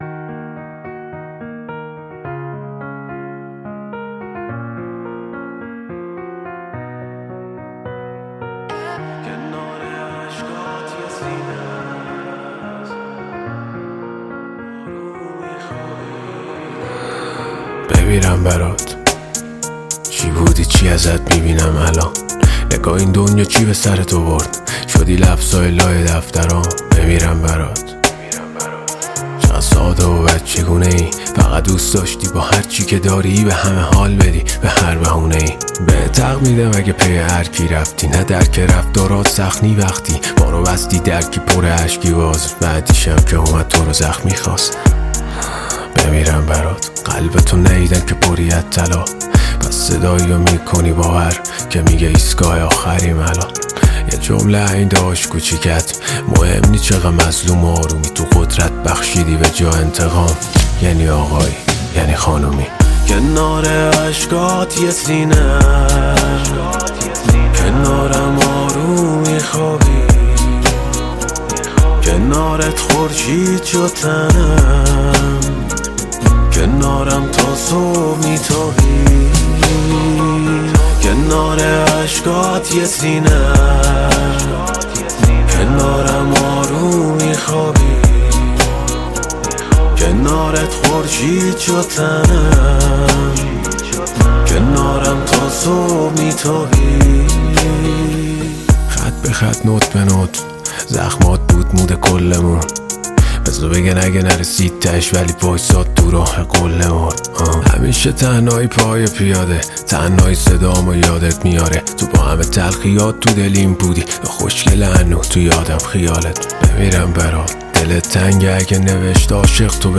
عاشقانه و غمگین